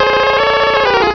Cri de Feunard dans Pokémon Rubis et Saphir.